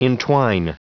Prononciation audio / Fichier audio de ENTWINE en anglais
Prononciation du mot entwine en anglais (fichier audio)